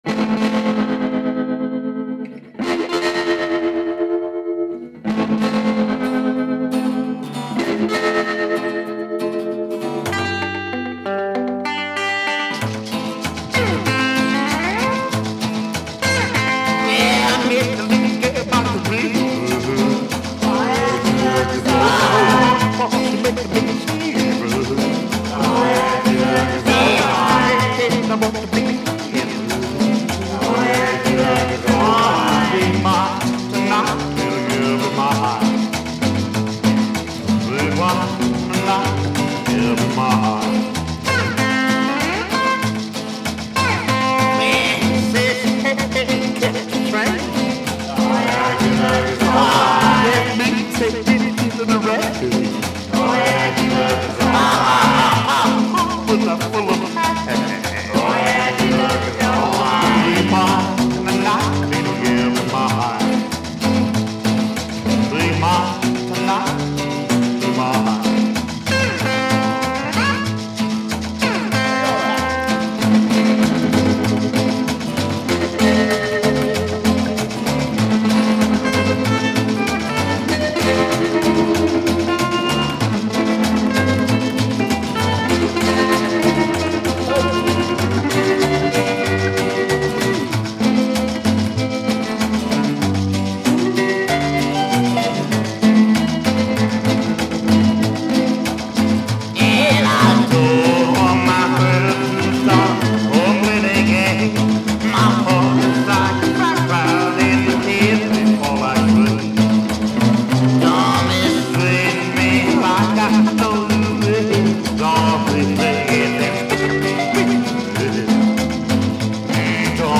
rockers